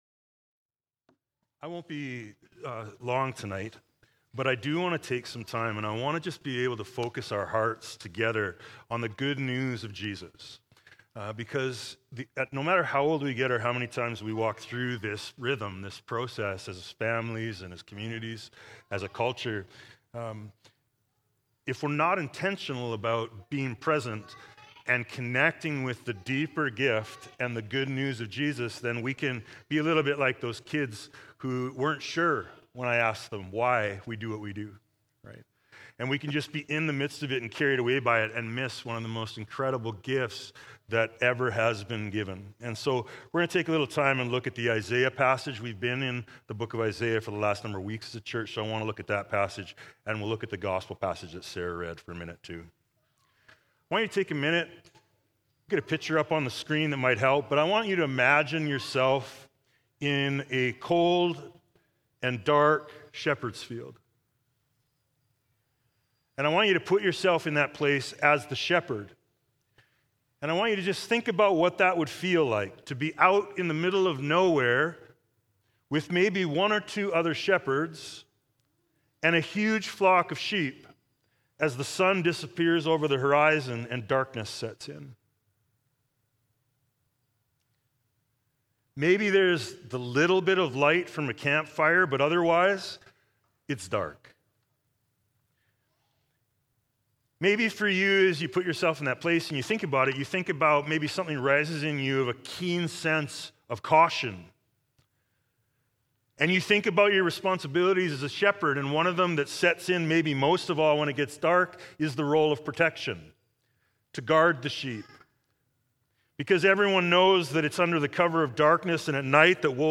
Sermons | Emmaus Road Anglican Church
Christmas Eve